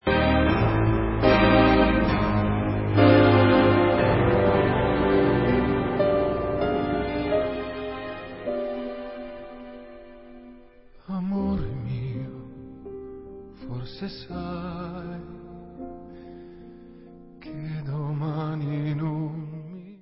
On Piano